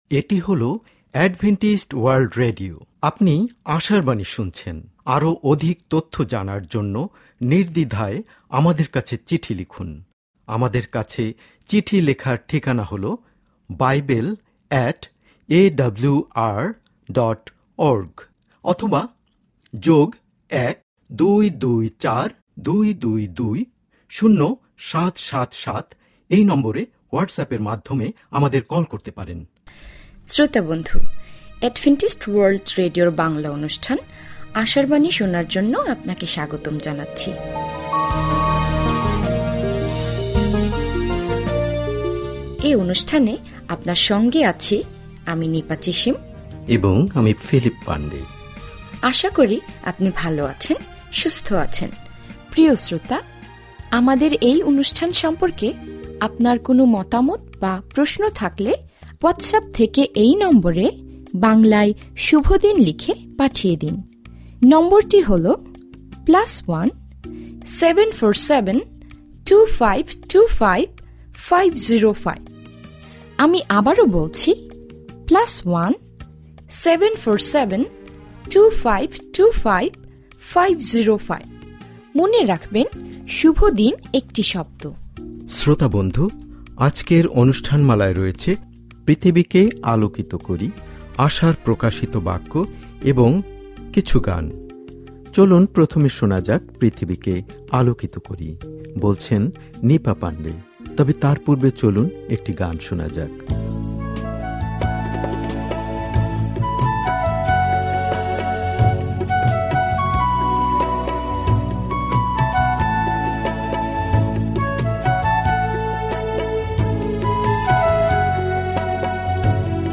ဓမ္မသီချင်း။တရားဒေသနာ။